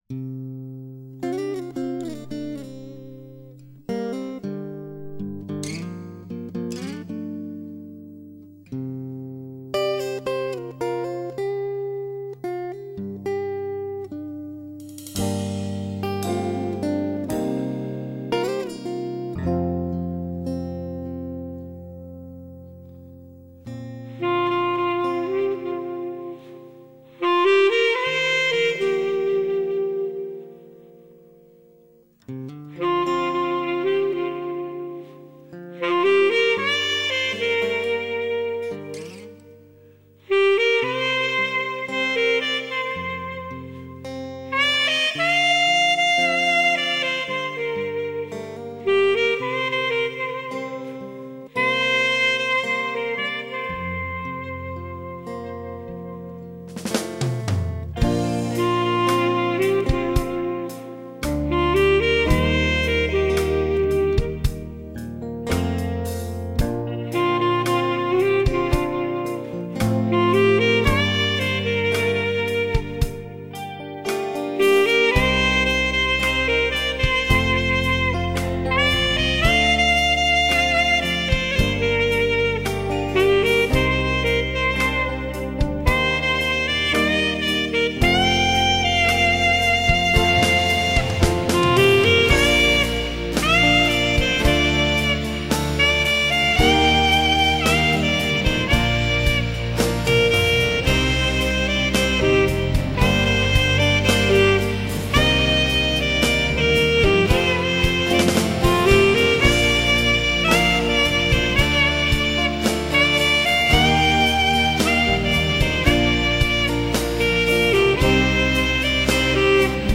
汽车音乐
萨克斯